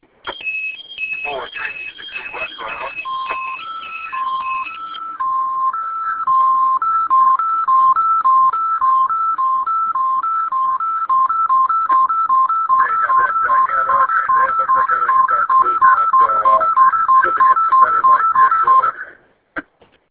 All-cab-sounds.wav